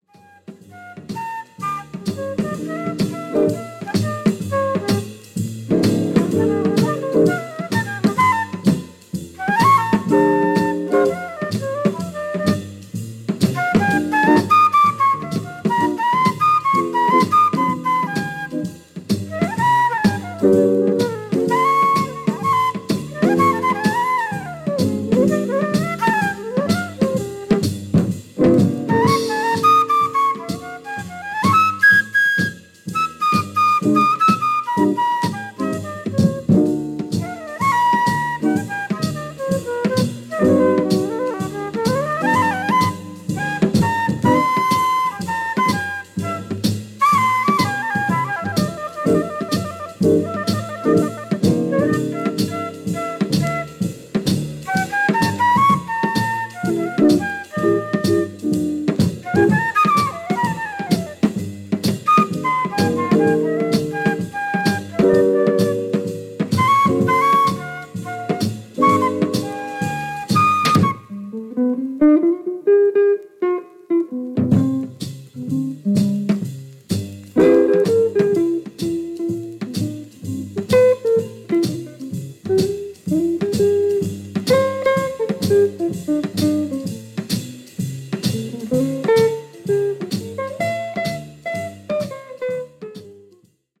Flute, Alto Saxophone
Bass
Cello
Drums, Percussion